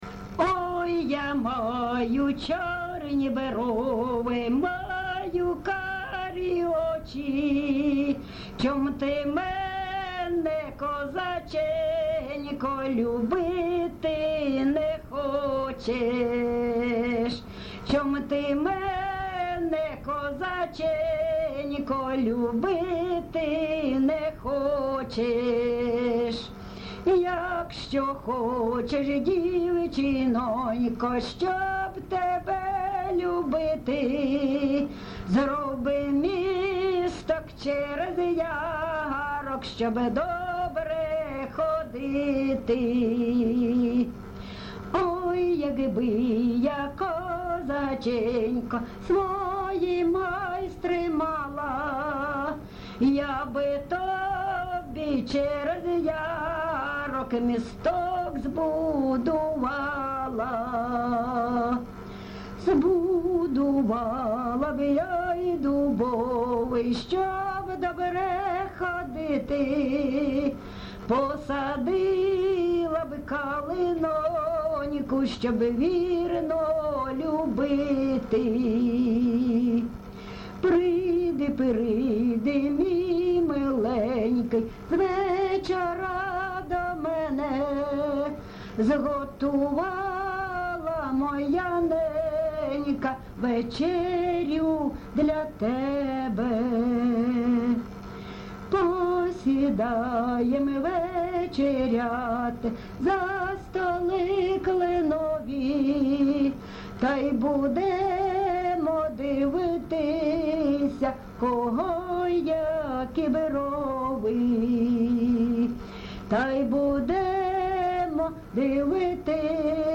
ЖанрПісні з особистого та родинного життя, Сучасні пісні та новотвори
Місце записус. Лозовівка, Старобільський район, Луганська обл., Україна, Слобожанщина